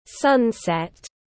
Mặt trời lặn tiếng anh gọi là sunset, phiên âm tiếng anh đọc là /ˈsʌn.set/
Sunset /ˈsʌn.set/